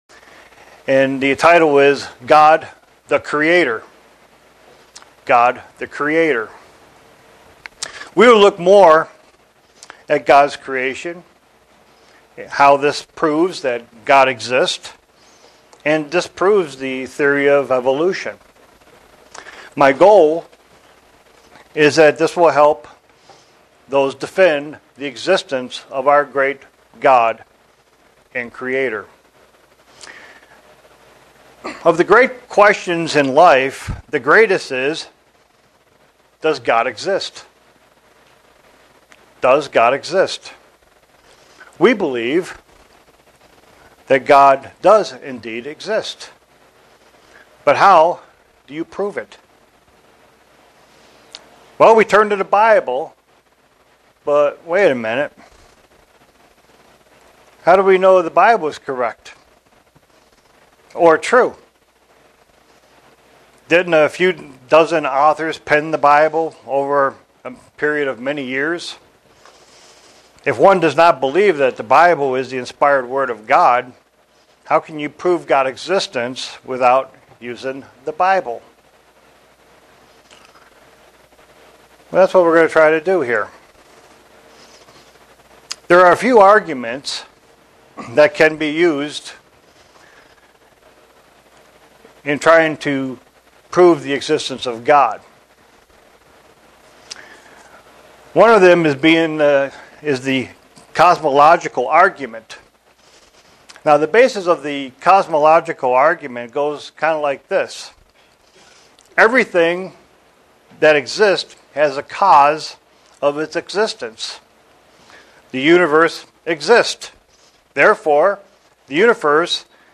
Given in Buffalo, NY
Print Ways to defend the existence of our Creator, with the Bible and without the Bible. sermon Studying the bible?